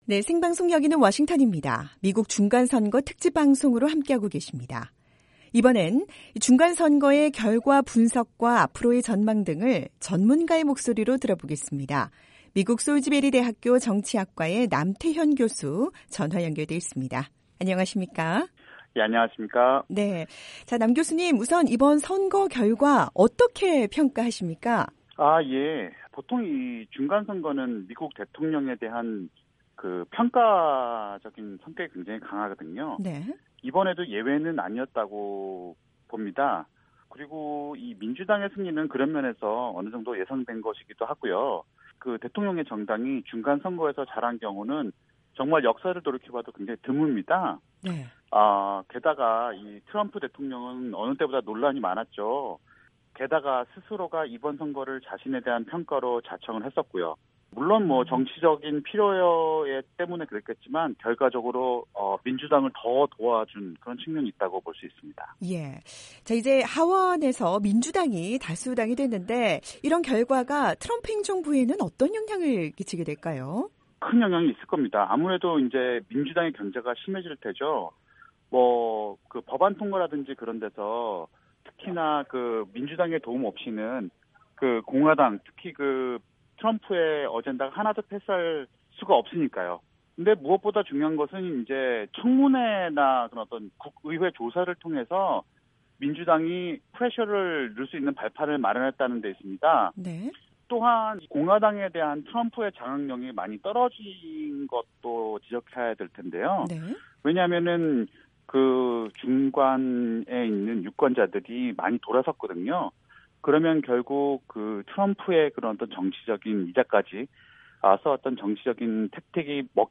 미국 중간선거 특집 방송으로 보내드리고 있는 '생방송 여기는 워싱턴입니다'.